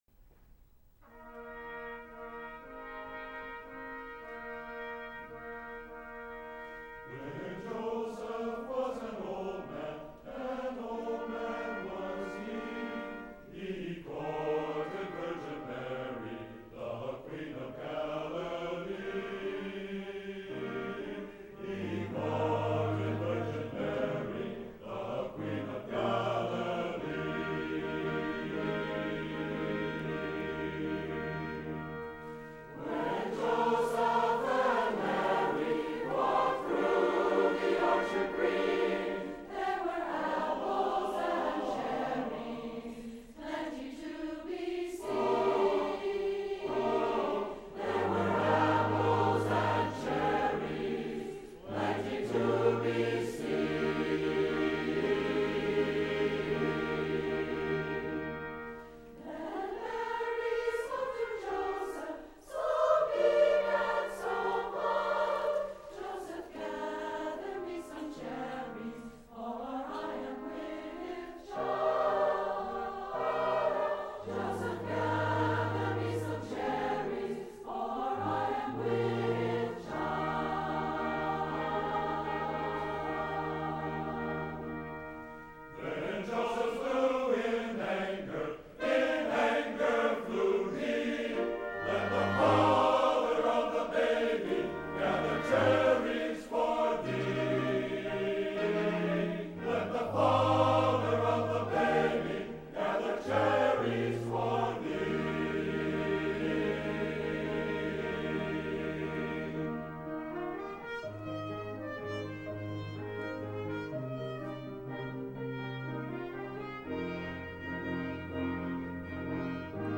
is a ballad.
SATB version